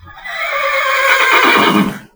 c_horsexxx_dead.wav